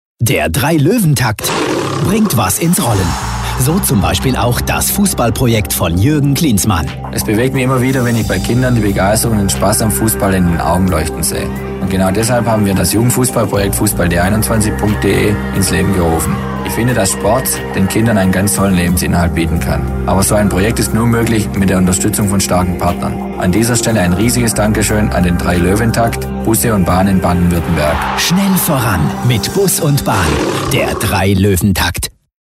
Jürgen Klinsmann für  3-Löwentakt – Funkspot – Radiowerbung